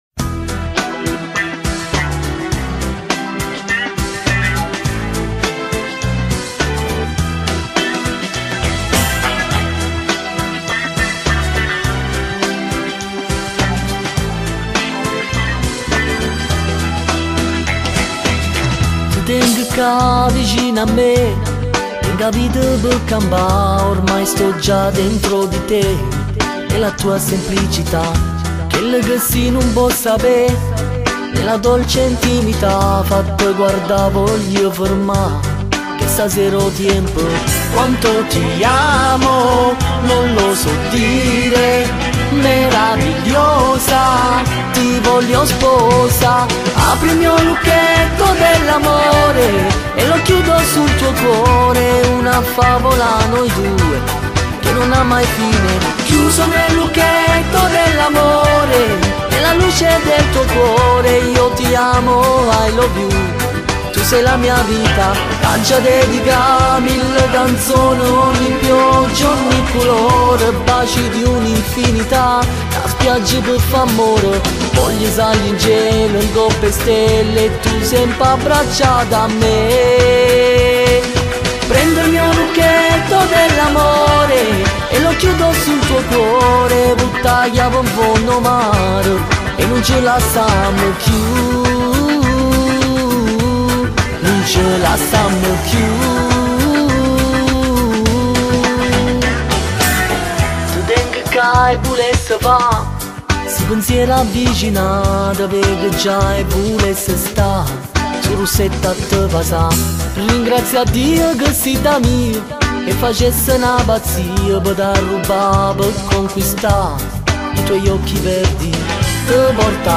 яркий представитель итальянской поп-музыки